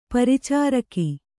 ♪ paricāraki